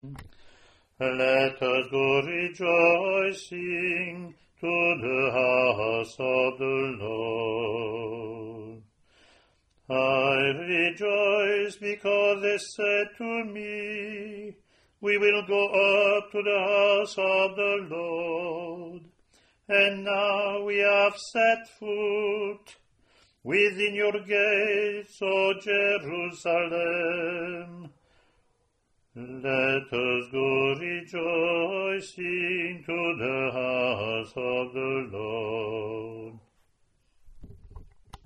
The Roman Missal gives us these Bible verses to be sung per the examples recorded: the congregation joins the cantor for the antiphon (printed), then the cantor sing the Psalm alone, then the congregation and cantor repeat the antiphon.
Year C Responsorial (English)